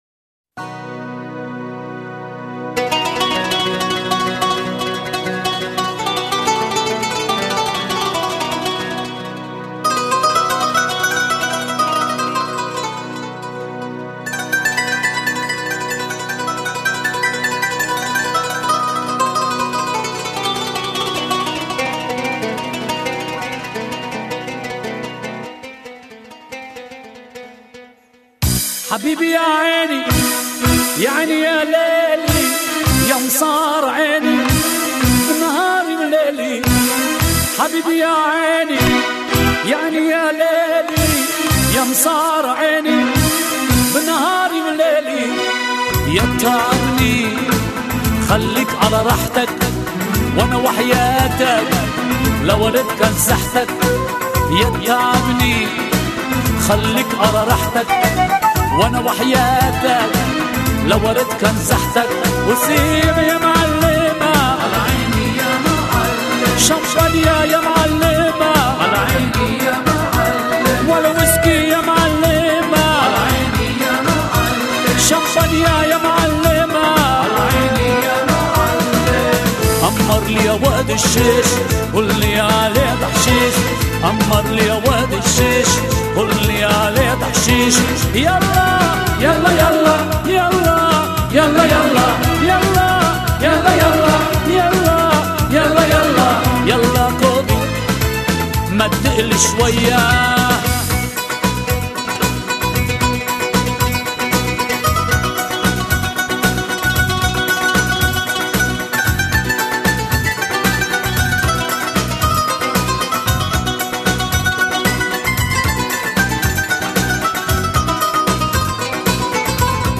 Middle Eastern Dance